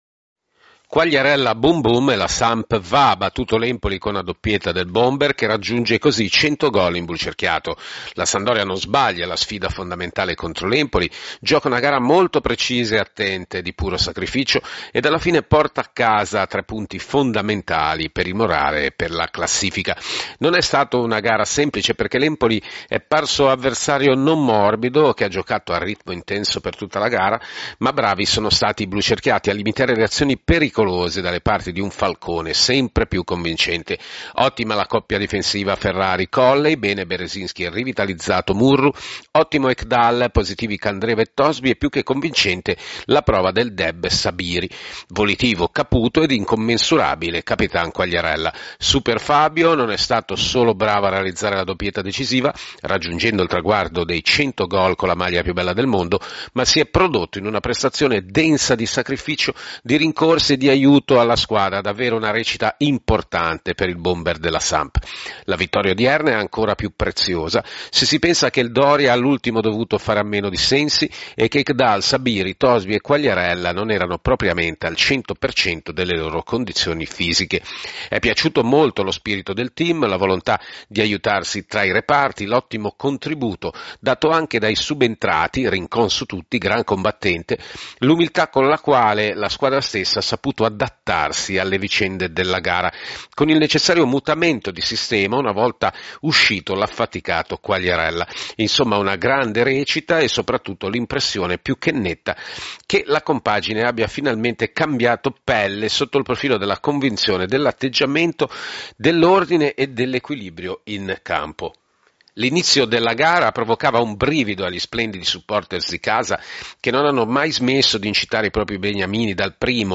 Nel servizio audio il commento e l’analisi